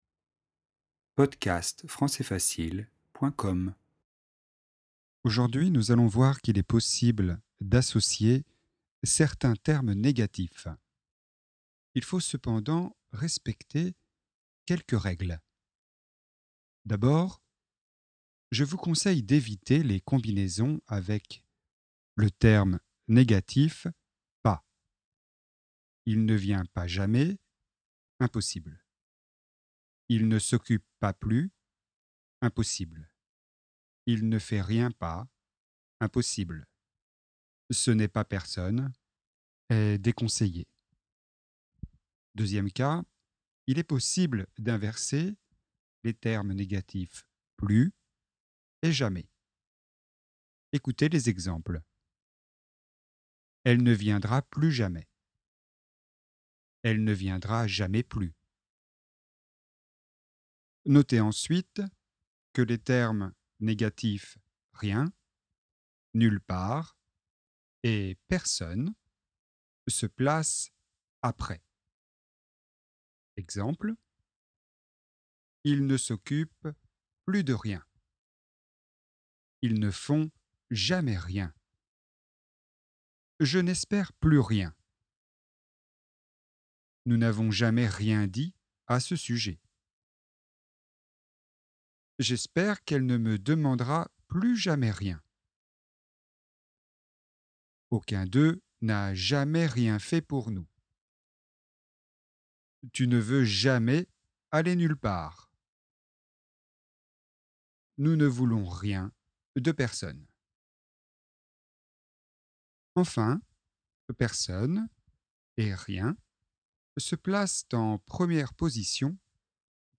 leçon forme négativene...aucunpersonne/rien